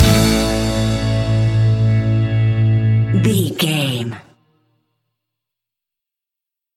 Uplifting
Ionian/Major
pop rock
fun
energetic
acoustic guitars
drums
bass guitar
electric guitar
piano
organ